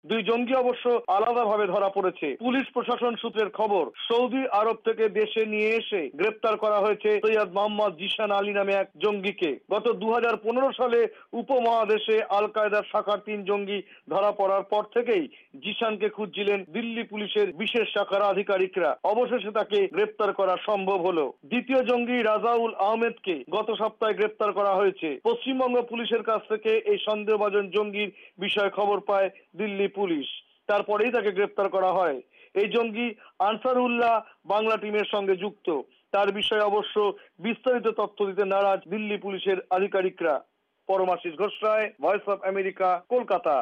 কলকাতা থেকে
রিপোর্ট